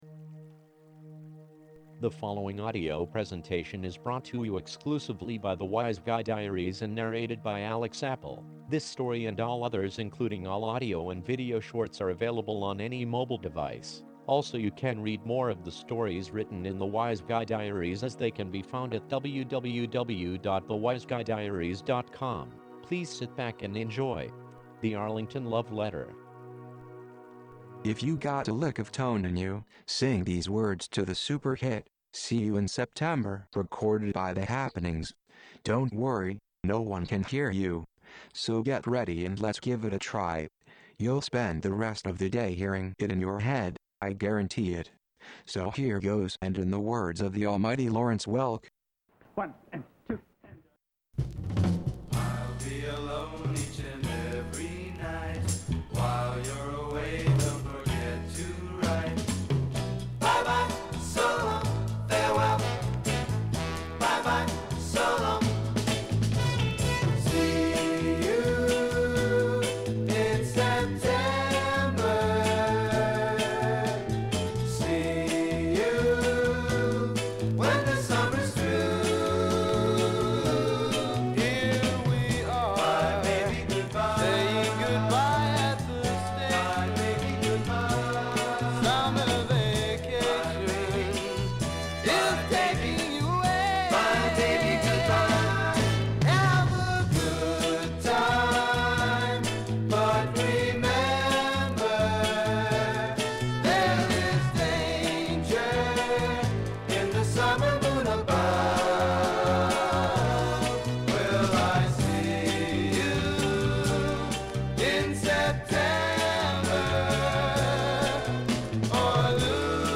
To LISTEN to the song only or the complete audio short story, press PLAY> You can always stop the audio and read. . .